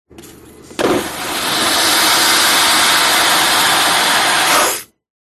Звуки смыва унитаза
Шум смыва собственных испражнений в самолете